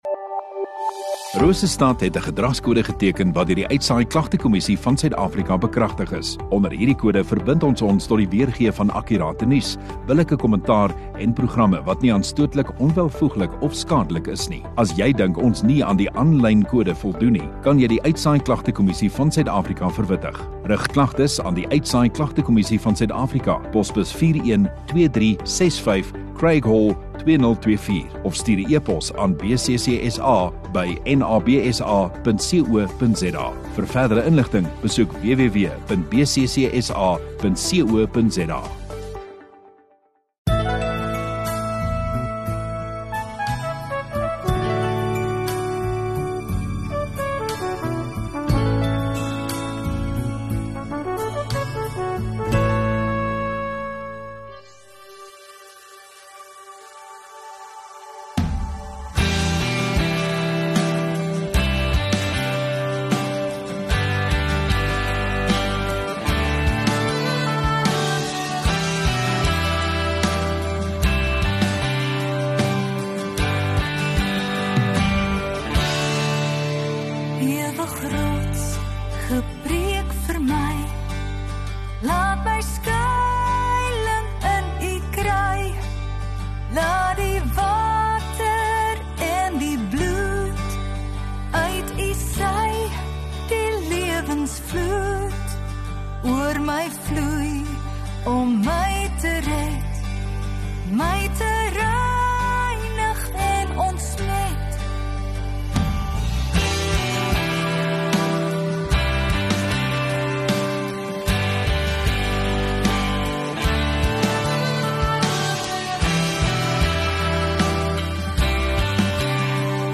13 Jul Saterdag Oggenddiens